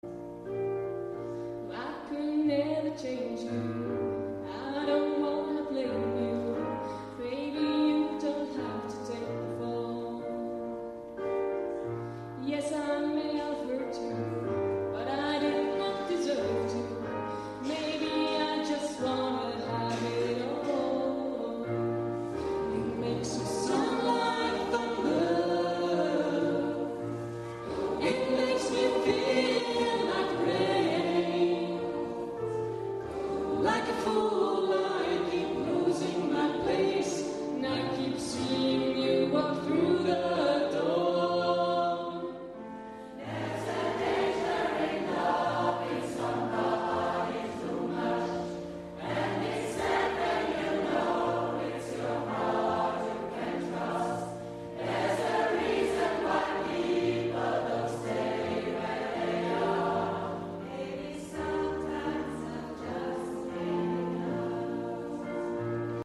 Live-Konzert vom 29. Oktober 2004 in der ref. Kirche Niederweningen
Piano, Drum